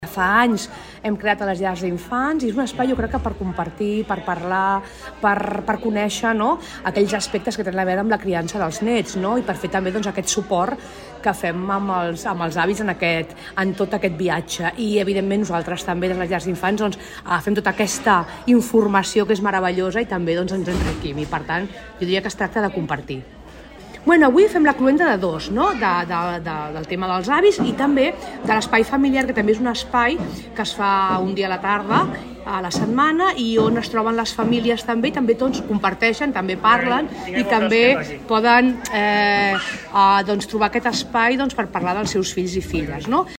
Soledad Rosende, regidora d'Ensenyament i Salut